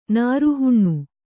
♪ nāru huṇṇu